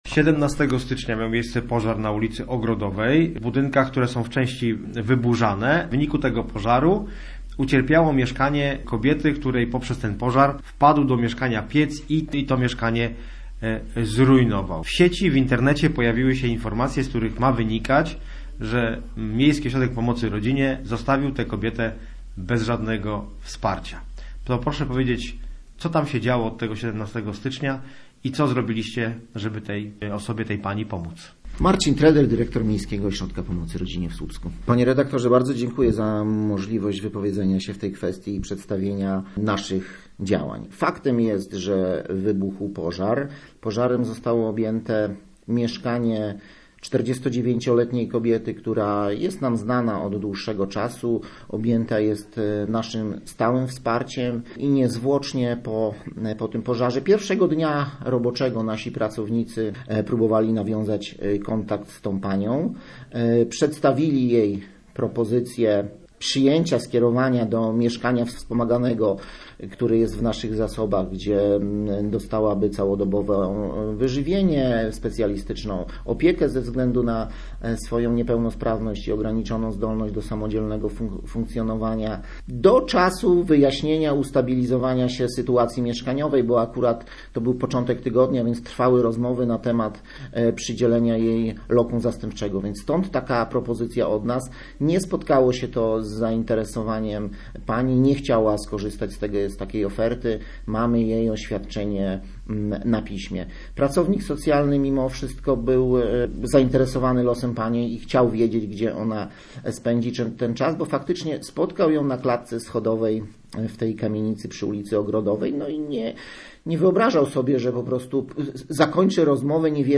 Posłuchaj materiału reportera Radia Gdańsk: https